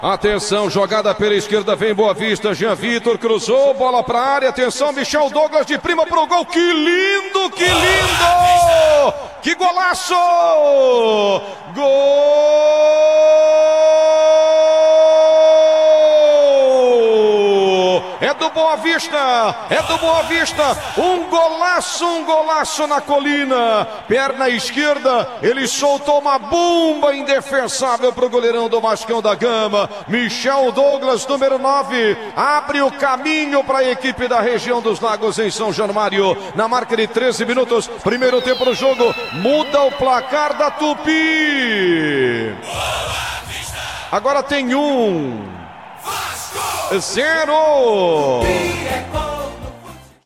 GOL-01-BOA-VISTA-1x0-VASCO.mp3